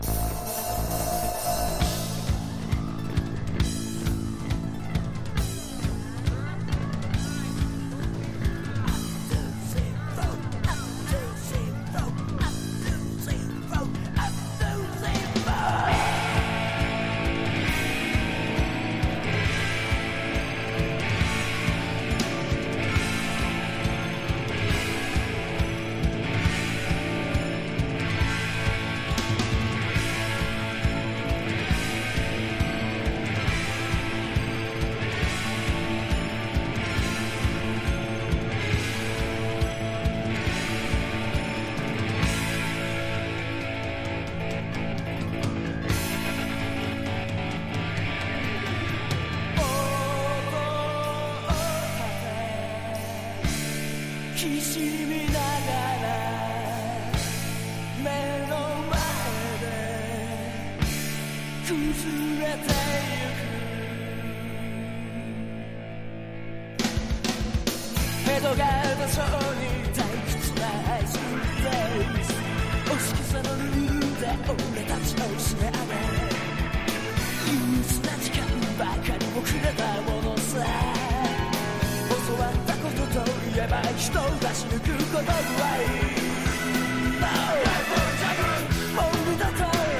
60年代のバンド・サウンドやガレージを下敷きにした刺激的なサウンドで突き抜ける全13曲収録！！
60-80’S ROCK